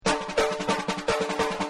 バテリア＆サンバ楽器
caixa.mp3